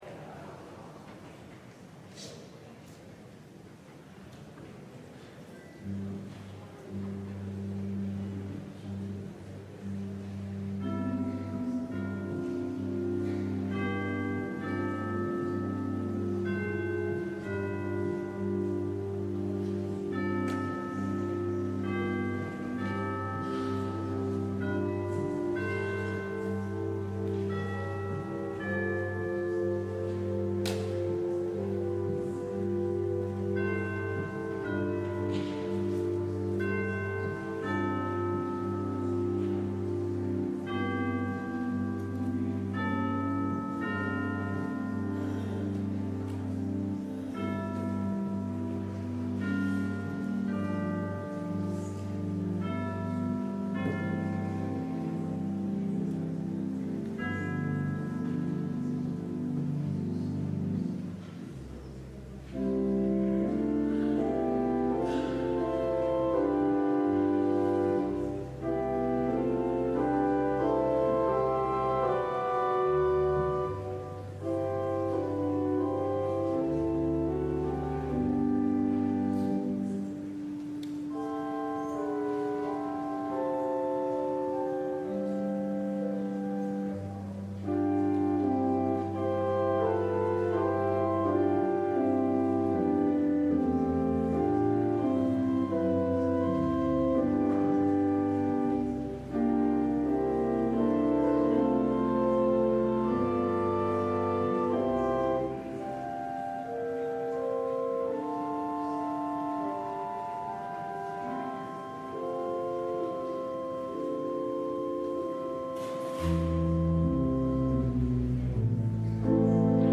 LIVE Morning Worship Service - Meaningful Work